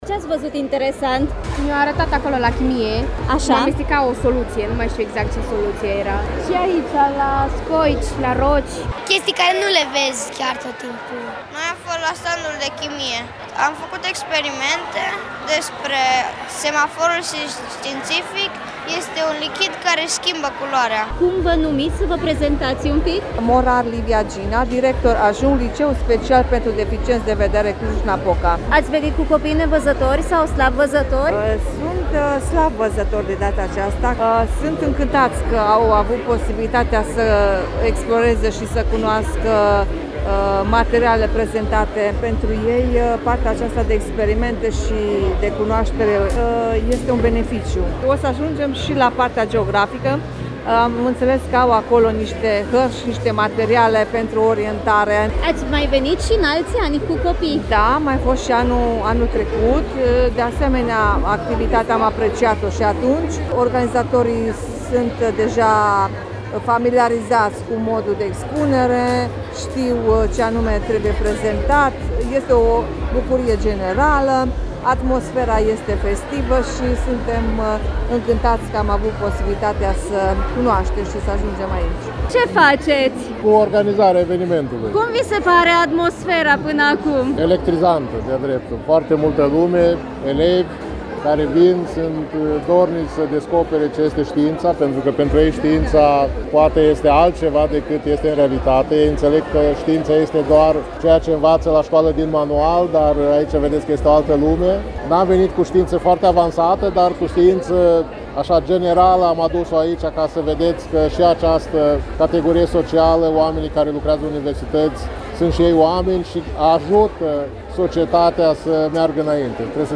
Vineri, 26 septembrie, platoul din fața Sălii Sporturilor și Parcul Central din Cluj-Napoca au prins viață la Noaptea Cercetătorilor Europeni.
Noaptea-cercetatorilor-29-septembrie.mp3